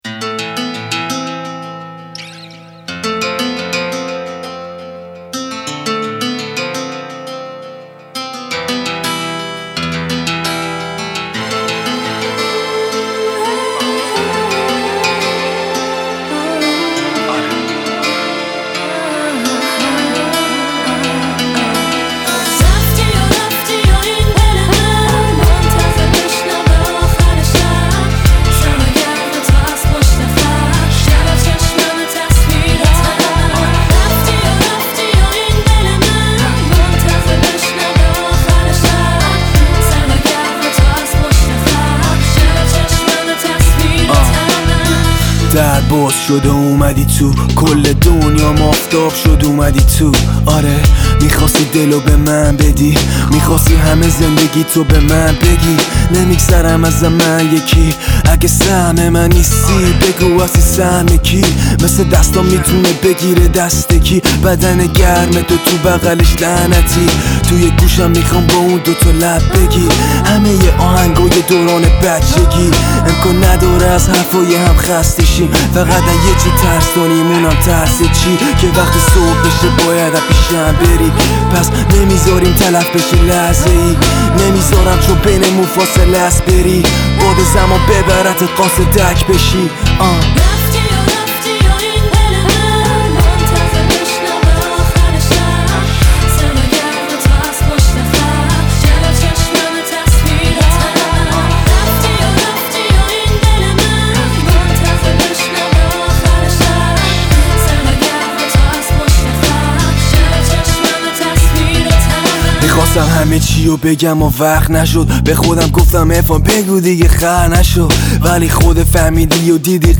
Sing: Hip Hop - آواز: هیپ ‌هاپ